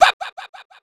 babumbumbum sounds
baBumBumBum_Farthest1.wav